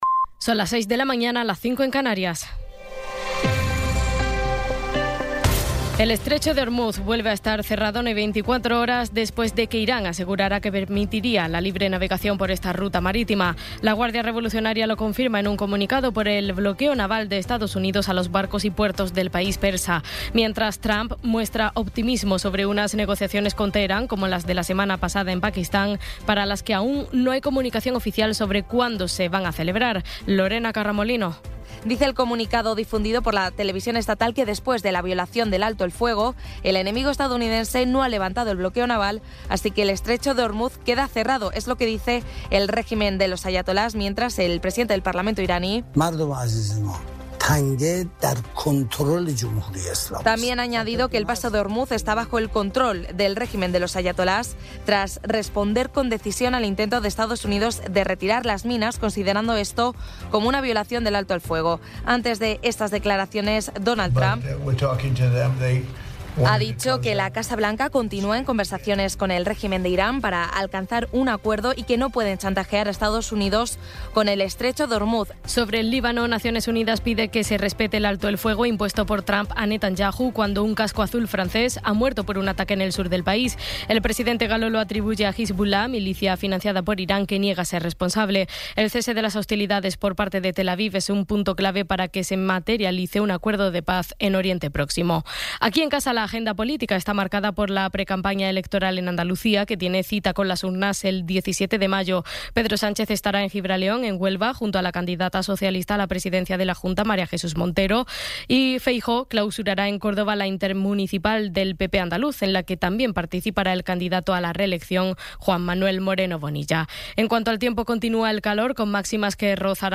Resumen informativo con las noticias más destacadas del 19 de abril de 2026 a las seis de la mañana.